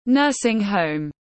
Viện dưỡng lão tiếng anh gọi là nursing home, phiên âm tiếng anh đọc là /ˈnɜː.sɪŋ ˌhəʊm/.
Nursing home /ˈnɜː.sɪŋ ˌhəʊm/
Nursing-home.mp3